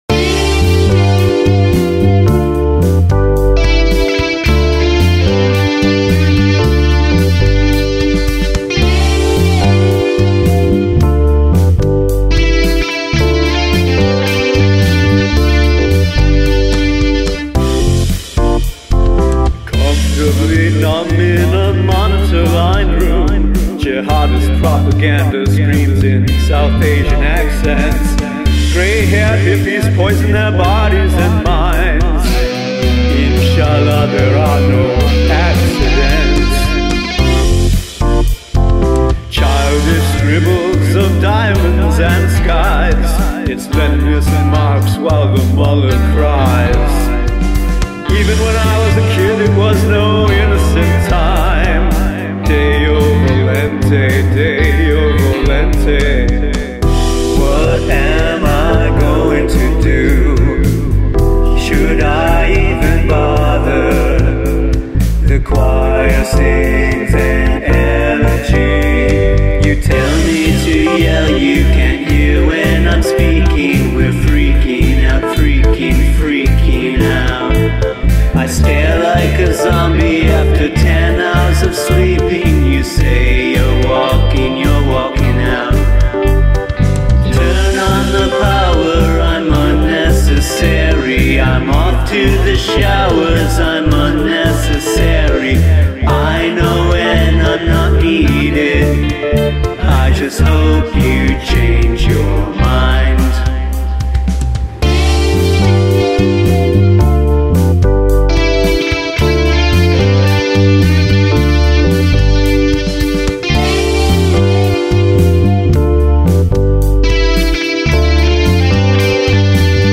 Psychedelic
Not as psychedelic as most of the others.